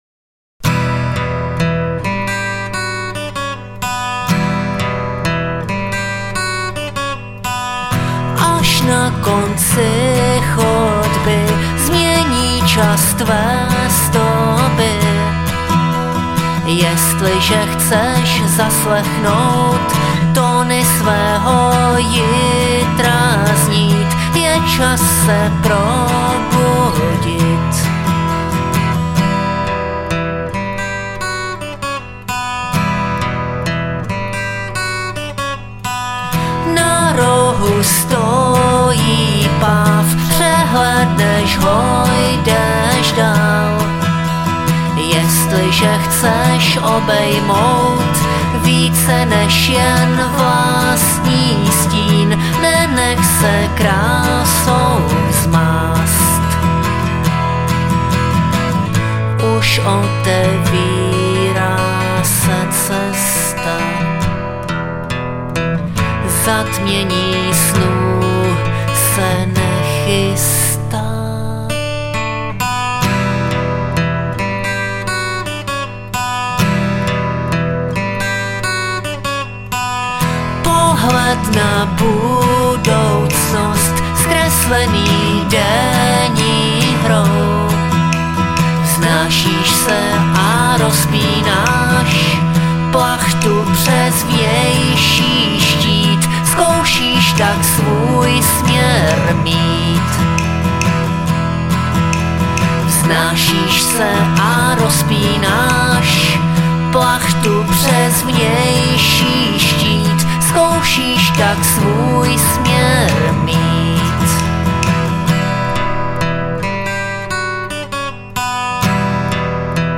Žánr: Indie/Alternativa
CD bizarního písničkáře z Kladna